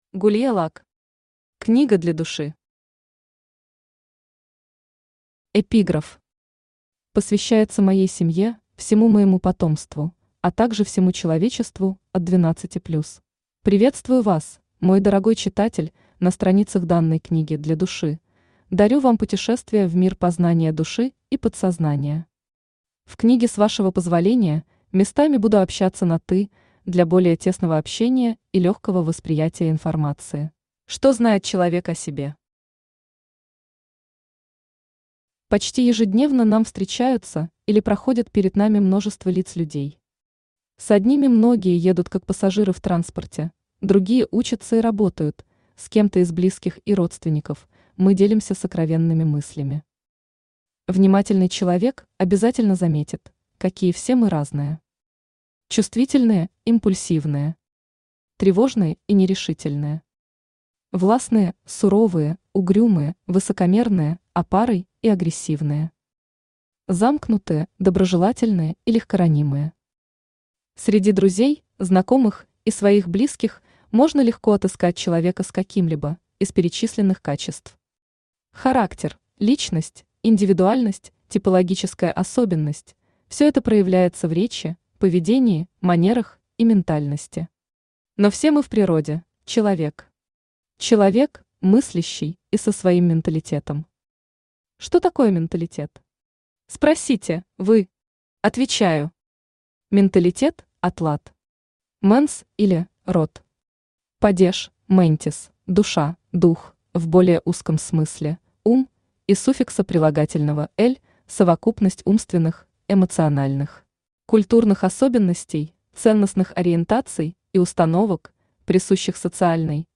Aудиокнига Книга для Души Автор Gulia Luck Читает аудиокнигу Авточтец ЛитРес.